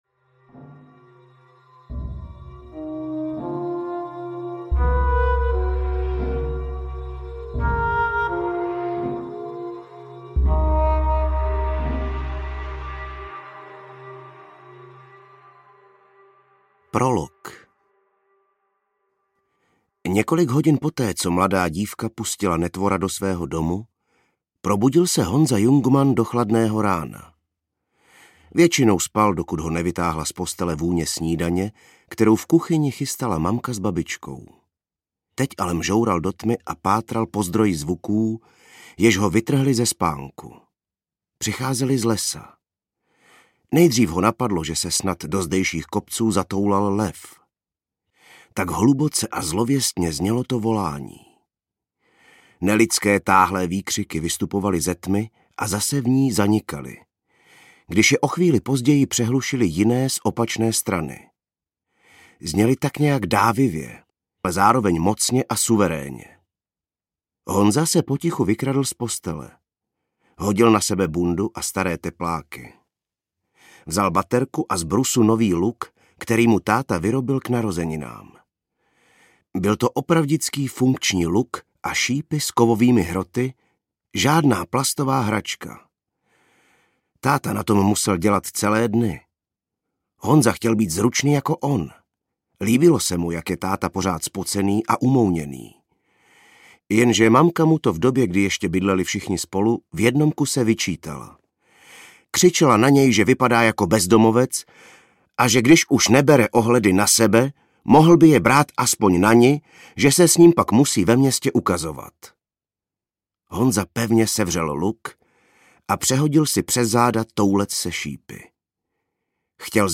Ukázka z knihy
slyset-jeleny-zpivat-audiokniha